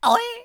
traf_damage8.wav